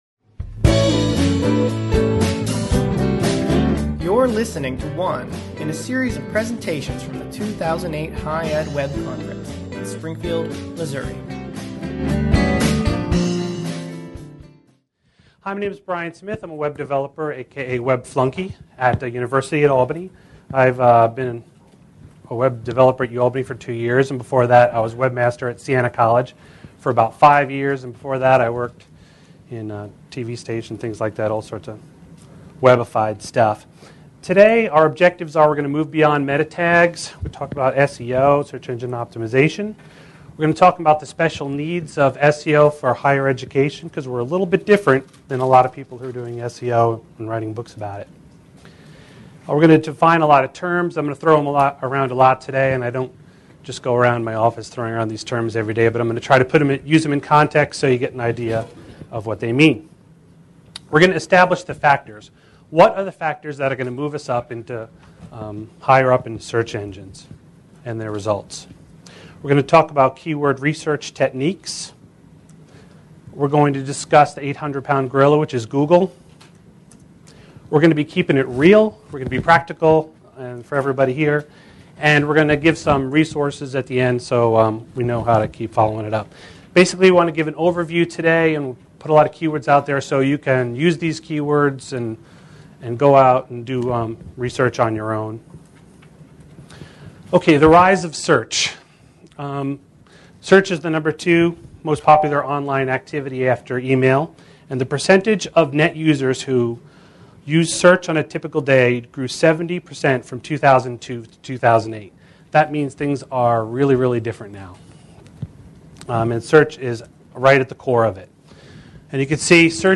Plaster Student Union Traywick Parliamentary Room